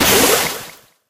water_brock_atk_01.ogg